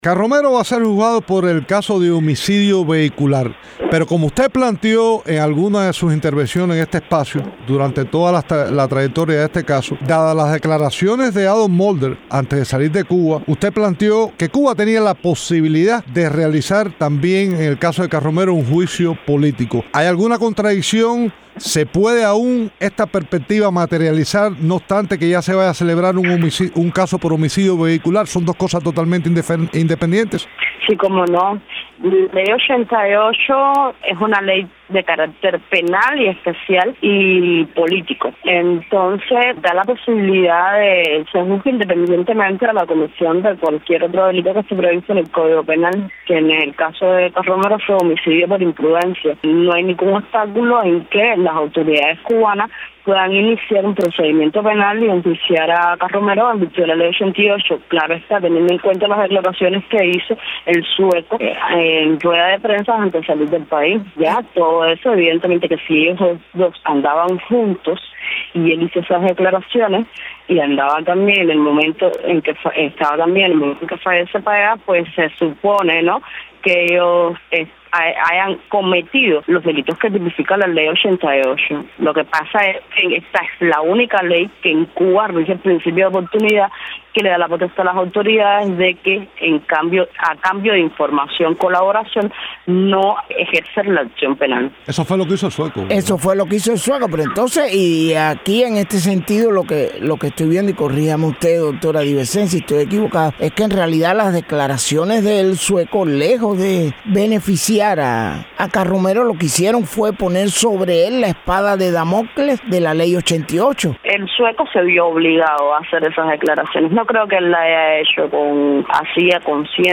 Durante el programa de Radio Martí, Cuba al Día